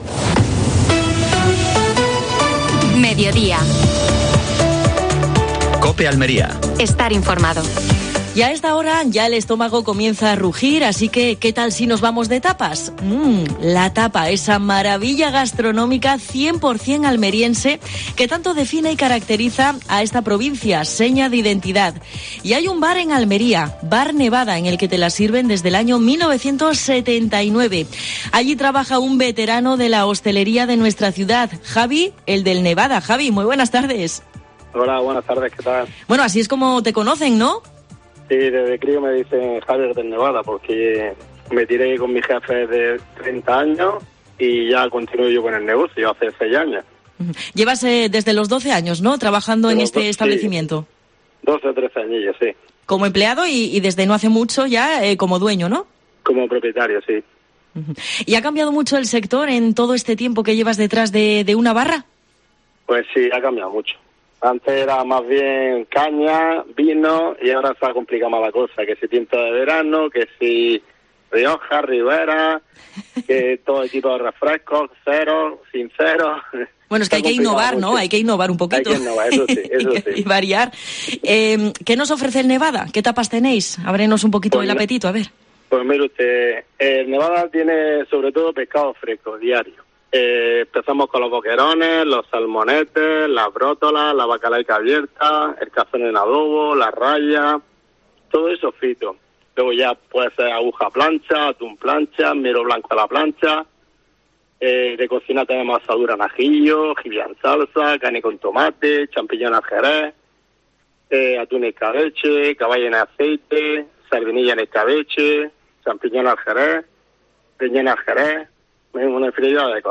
AUDIO: Entrevista, efemérides, deportes, actualidad.